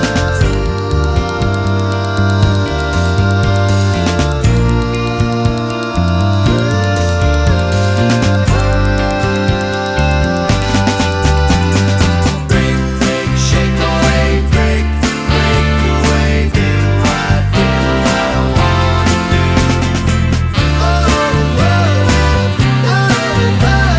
no Backing Vocals Pop (1960s) 2:56 Buy £1.50